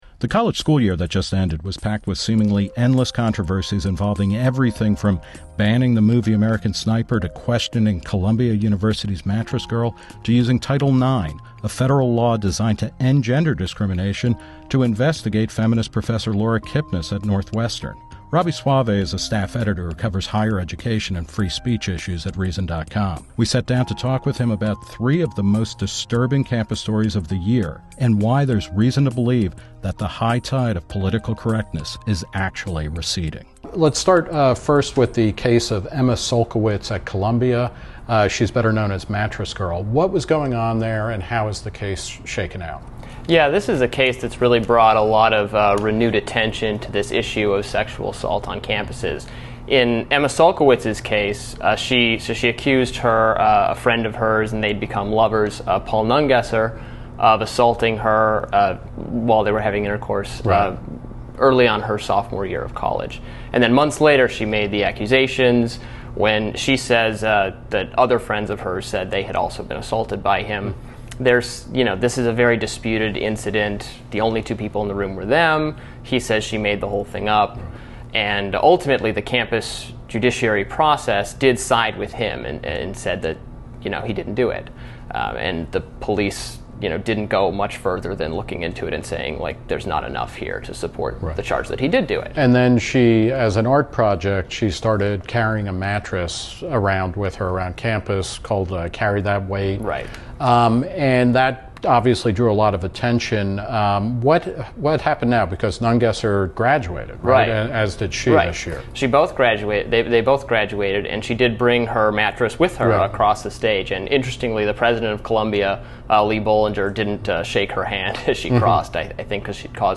He sat down with Nick Gillespie to talk about the state of free speech and ideological orthodoxy on campus today—and to explain why there are reasons to believe political correctness is actually on the run.